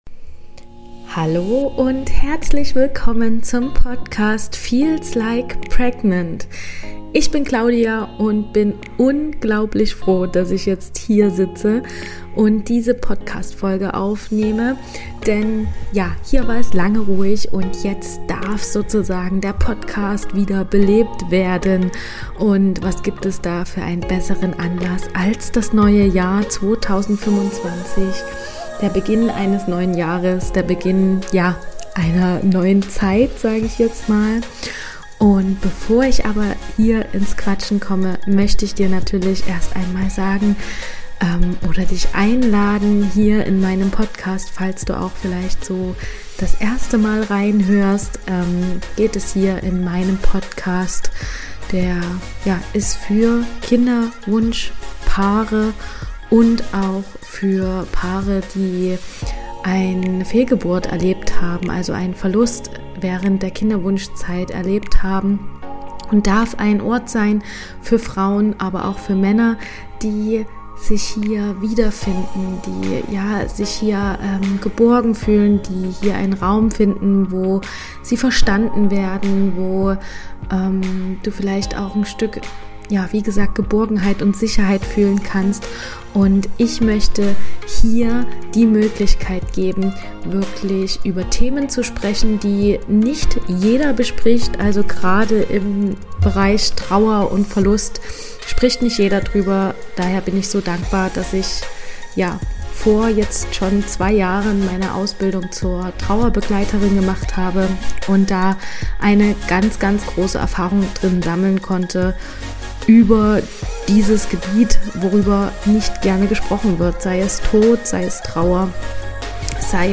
Lang ist es her.. und doch fühlt es sich so vertraut an, vor meinem Mikro zu sitzen und "mit dir" zu quatschen!